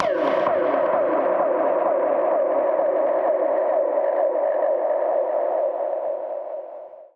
Index of /musicradar/dub-percussion-samples/134bpm
DPFX_PercHit_A_134-06.wav